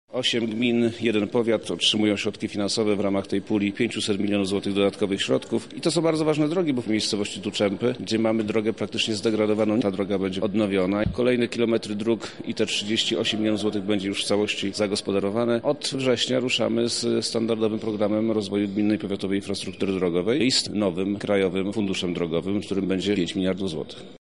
O tym dlaczego właśnie te jednostki otrzymały dofinansowanie mówi Wojewoda Lubelski, Przemysław Czarnek